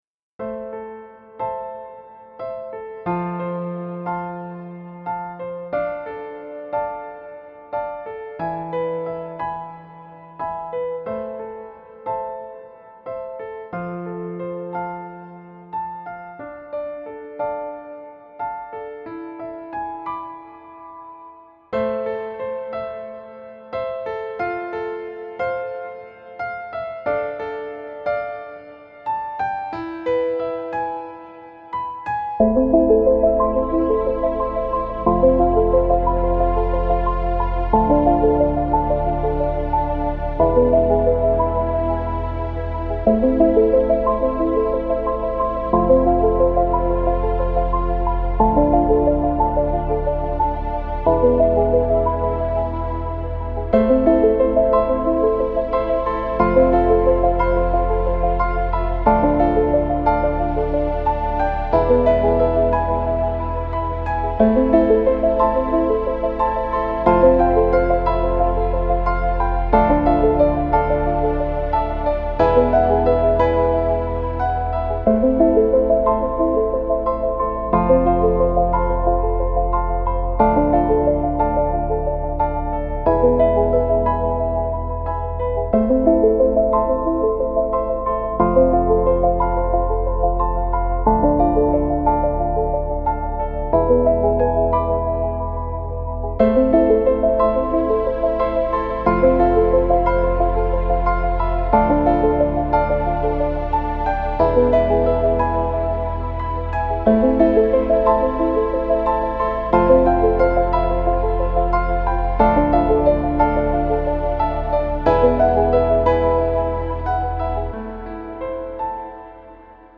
Dreamy Ambient Electronic Background Music
Genres: Background Music
Tempo: 90 bpm